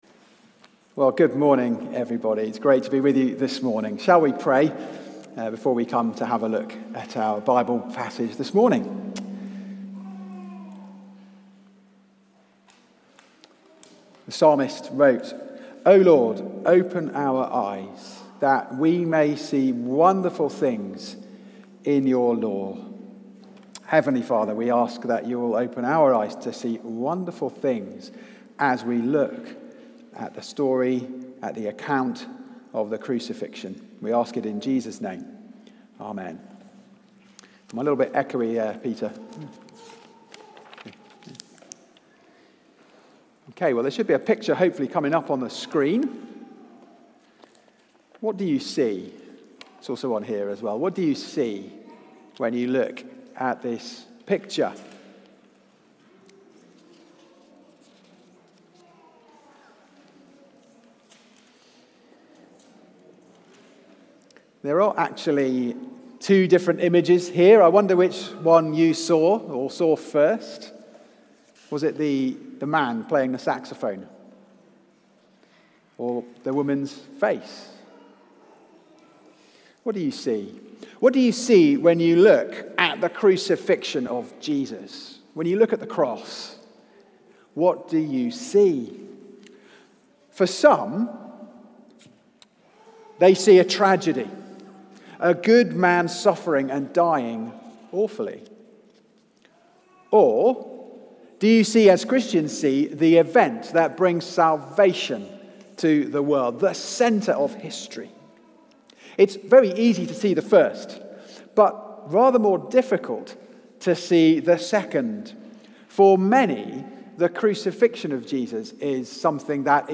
Series: Luke 18-24: Salvation Accomplished! Theme: Jesus is Crucified Sermon Search: